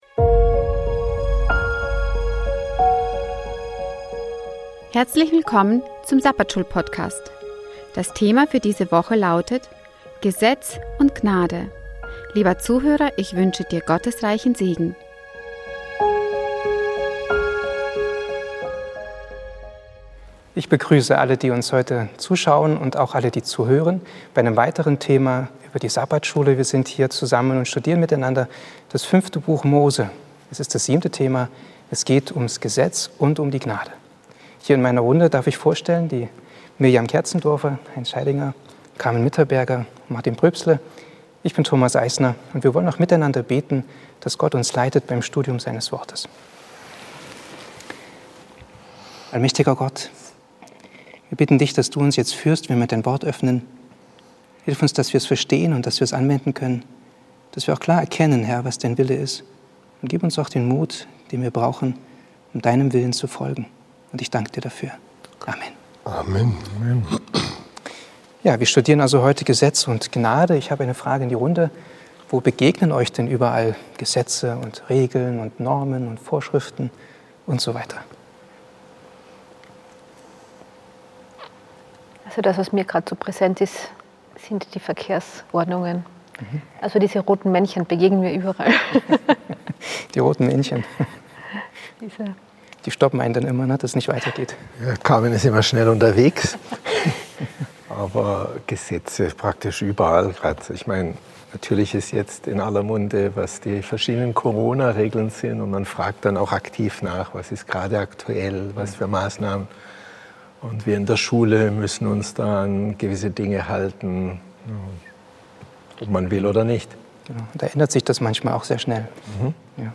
Hier hören sie das Sabbatschulgespräch aus Bogenhofen zur Weltfeldausgabe der Lektion der Generalkonferenz der Siebenten-Tags-Adventisten